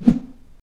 woosh.wav